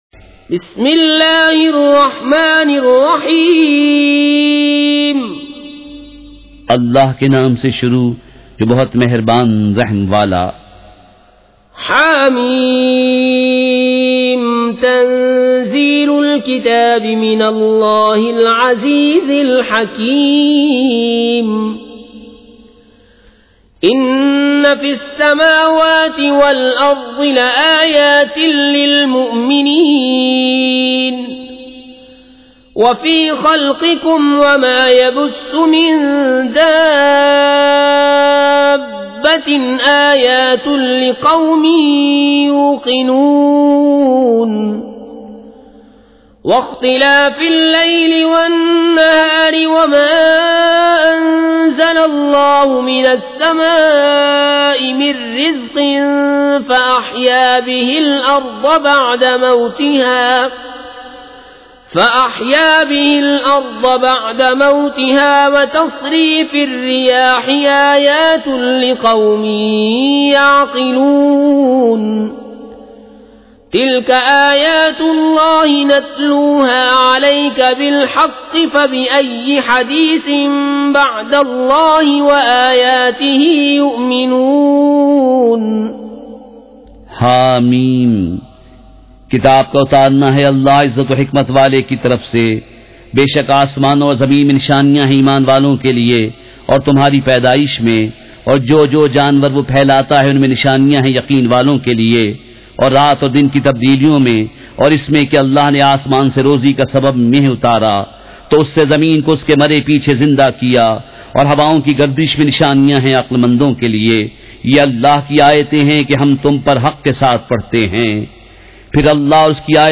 سورۃ الجاثیہ مع ترجمہ کنزالایمان ZiaeTaiba Audio میڈیا کی معلومات نام سورۃ الجاثیہ مع ترجمہ کنزالایمان موضوع تلاوت آواز دیگر زبان عربی کل نتائج 2613 قسم آڈیو ڈاؤن لوڈ MP 3 ڈاؤن لوڈ MP 4 متعلقہ تجویزوآراء